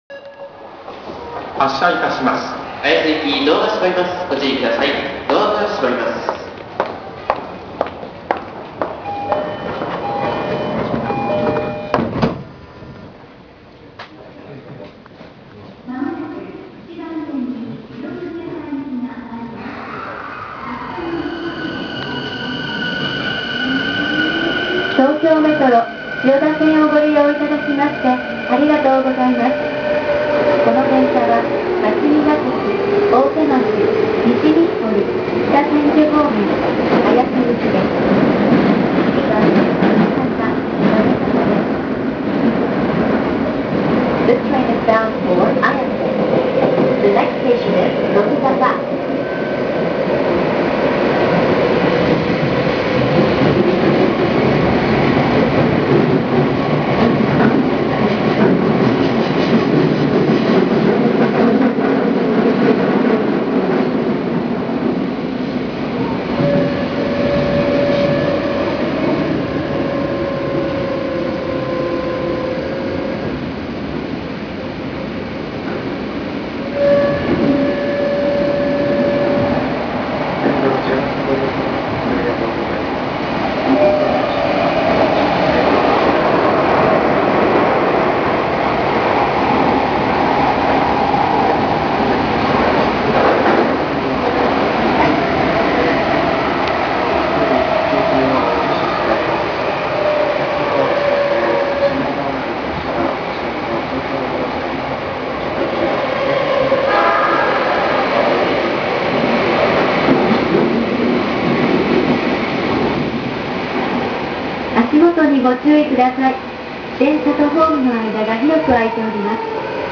・4000形走行音
【東京メトロ千代田線】表参道〜乃木坂（2分22秒：774KB）
起動音こそE233系に似ていますが、一度変調すると全く違う音になります（三菱のIGBTである事自体は同様）。